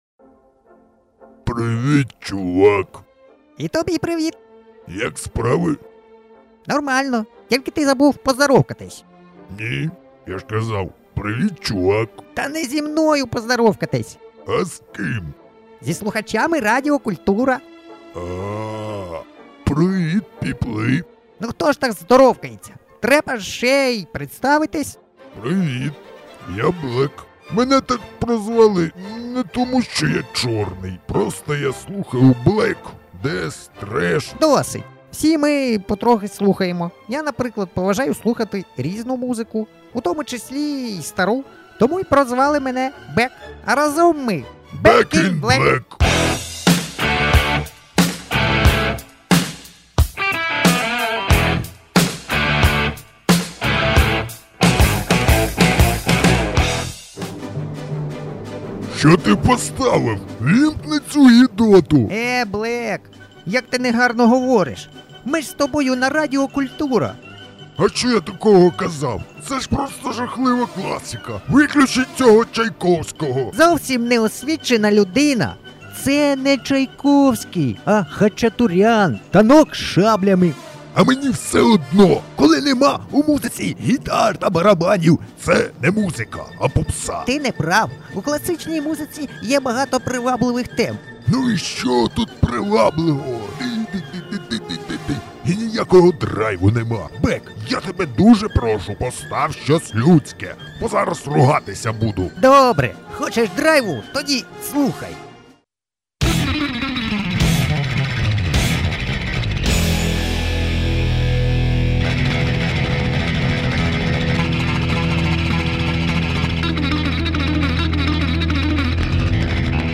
Радиопередача Hard Rock Heavy Metal